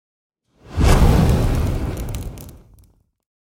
دانلود آهنگ آتش 1 از افکت صوتی طبیعت و محیط
دانلود صدای آتش 1 از ساعد نیوز با لینک مستقیم و کیفیت بالا
جلوه های صوتی